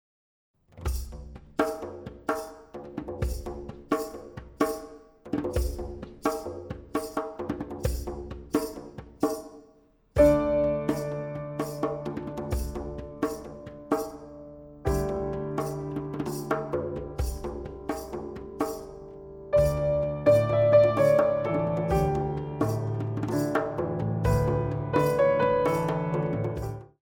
11 tracks of instrumental compositions for Modern Dance
Piano & Percussion